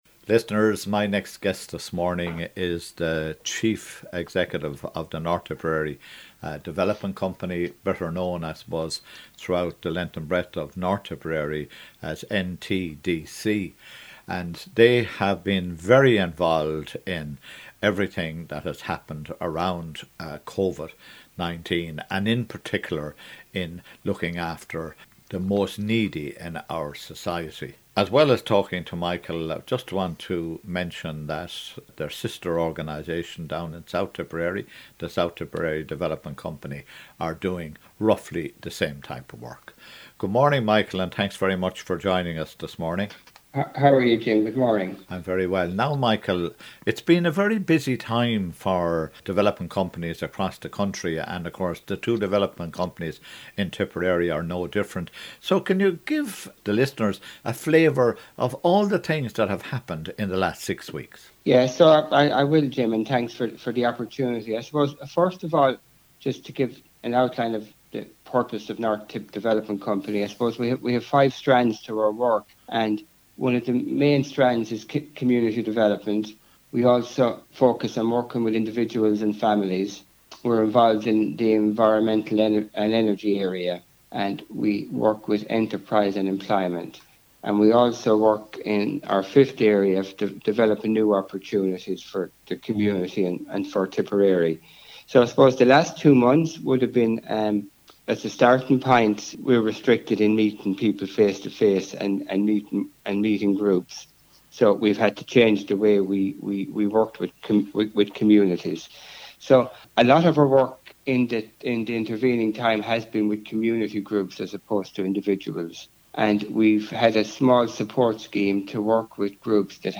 Interviews